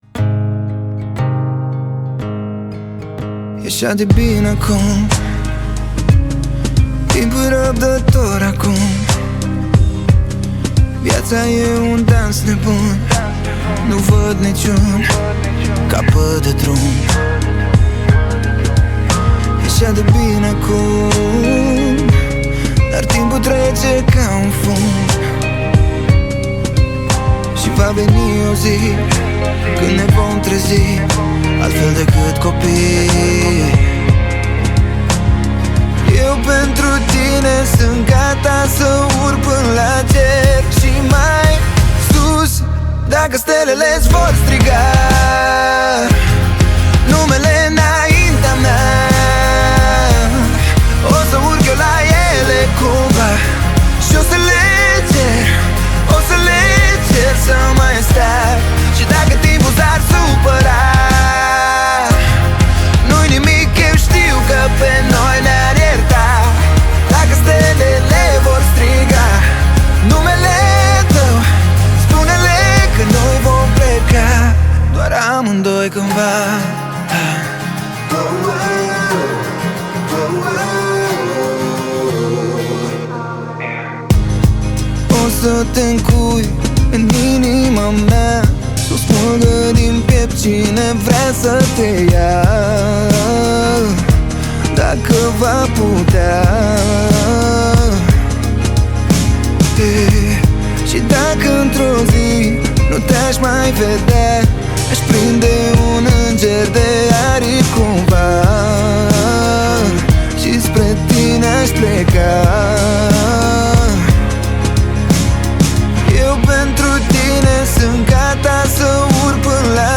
o piesă care îmbină emoție și ritm, ideală pentru relaxare
Muzica Usoara